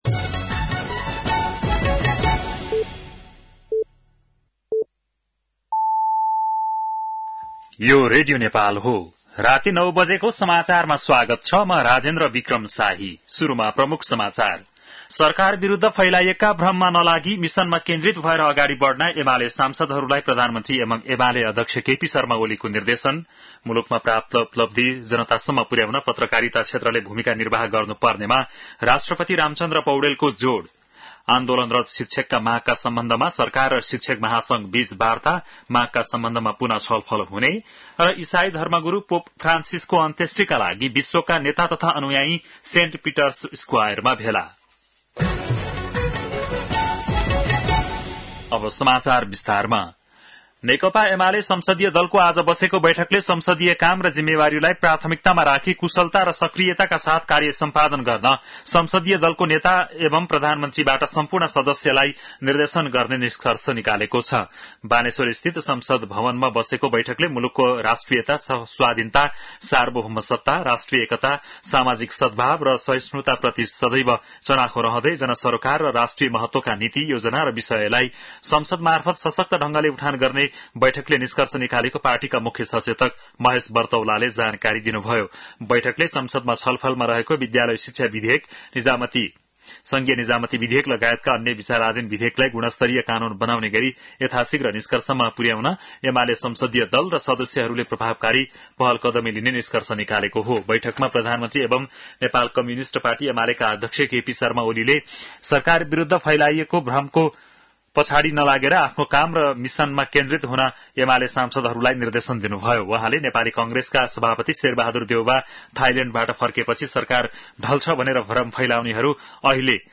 बेलुकी ९ बजेको नेपाली समाचार : १३ वैशाख , २०८२